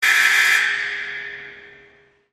На этой странице собраны звуки тюрьмы — от хлопающих дверей камер до приглушенных разговоров в коридорах.
Звуковой сигнал открытия двери бип